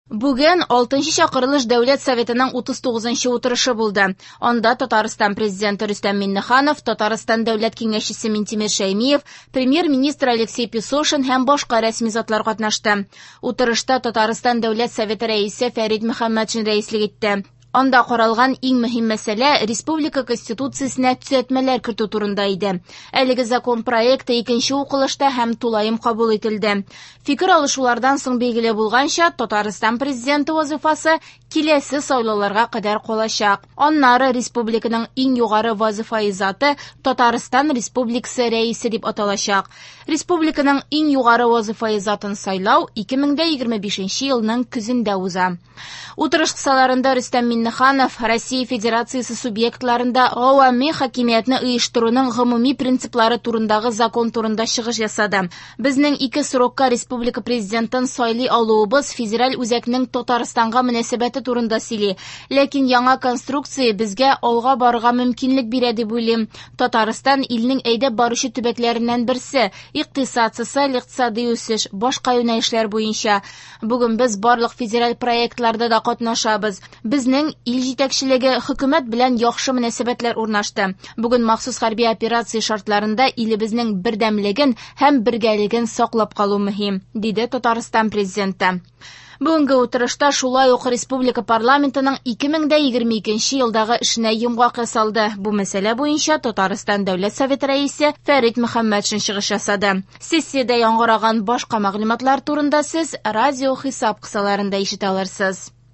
Радиоотчет (23.12.22)
В эфире специальный информационный выпуск, посвященный 39 заседанию Государственного Совета Республики Татарстан 6-го созыва.